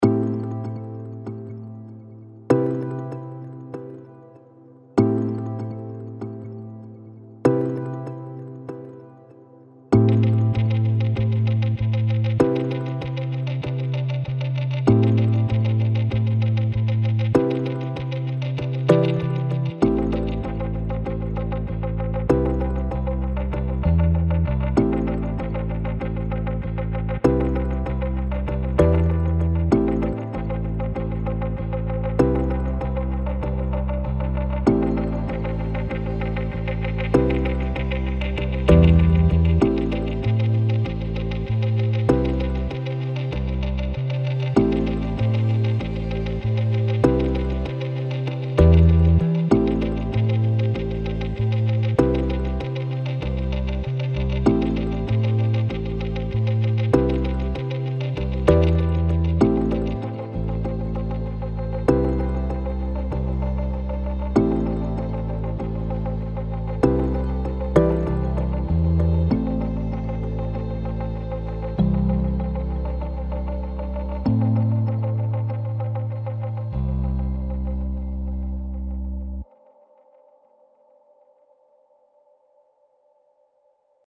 without beat